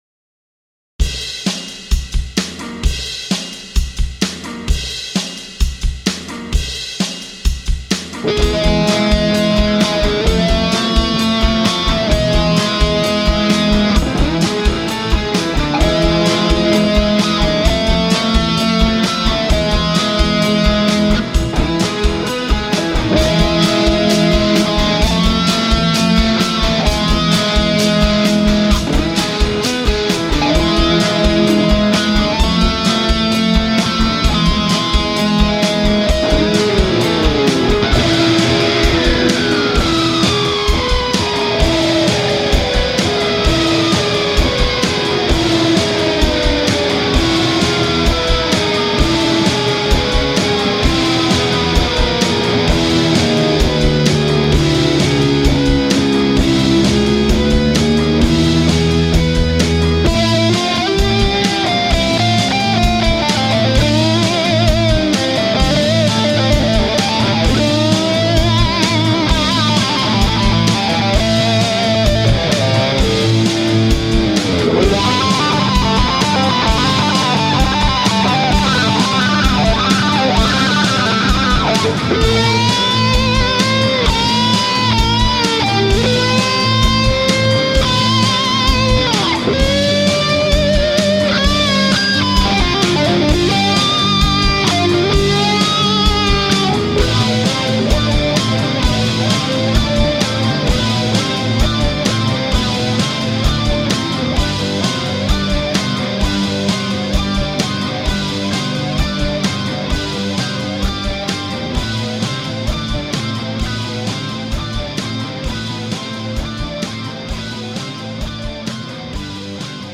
OK then - A quick variation of the second one with a few octaves of bass thrown in.
See if your keyboard Am and G jive starting at the 1:00 minute mark on the tune below.
I could go back to the opening E, F thing again.